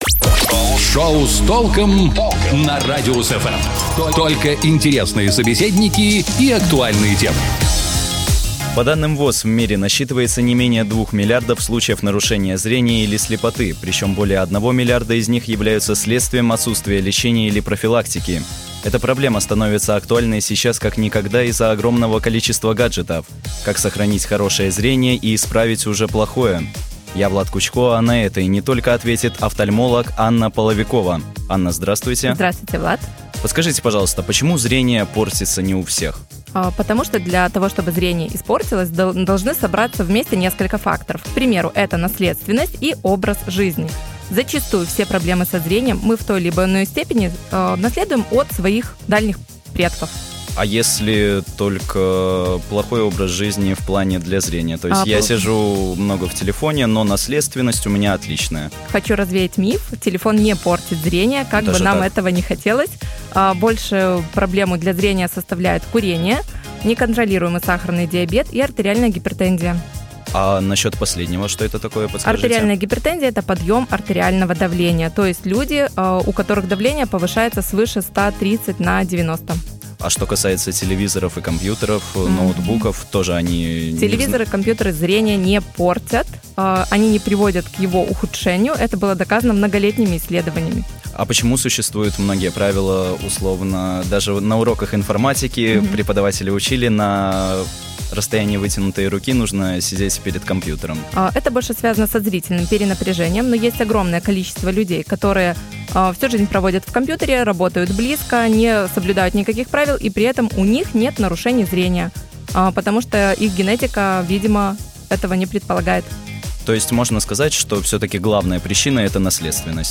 Как сохранить хорошее зрение и исправить уже плохое. На это ответит офтальмолог